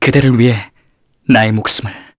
Theo-Vox_Victory_kr.wav